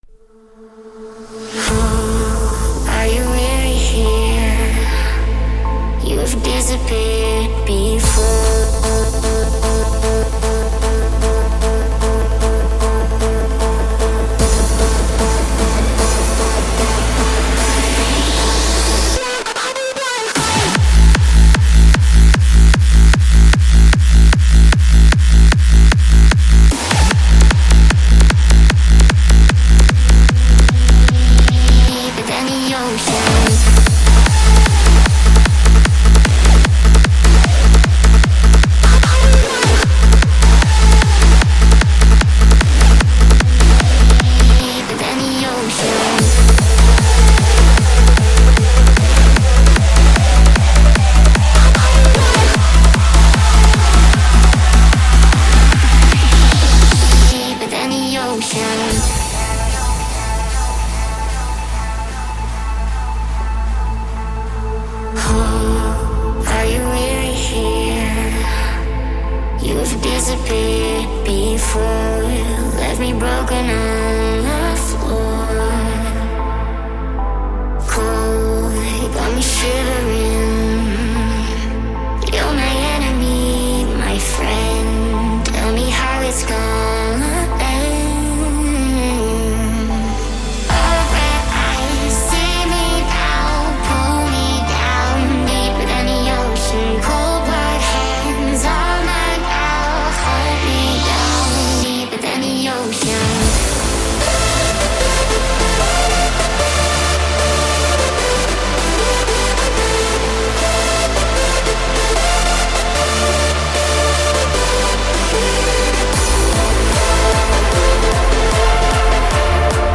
Quarantine Livestreams Genre: Hardstyle